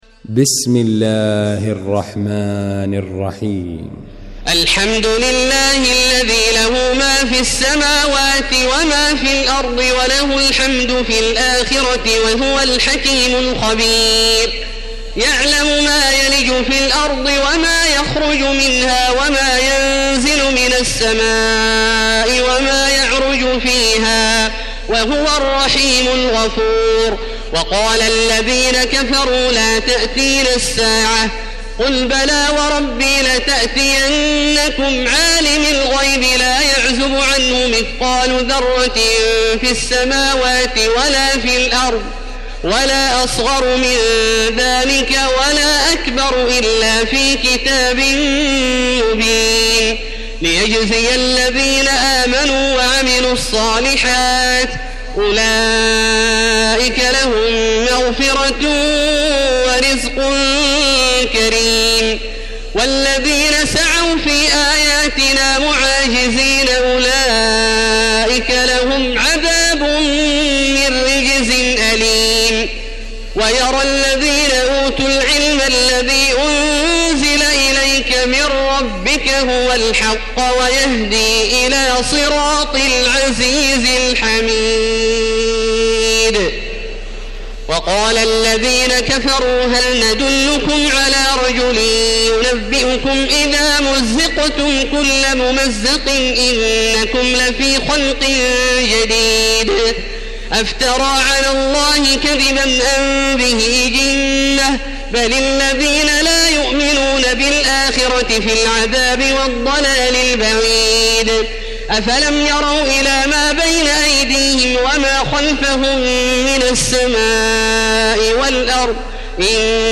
المكان: المسجد الحرام الشيخ: فضيلة الشيخ عبدالله الجهني فضيلة الشيخ عبدالله الجهني سبأ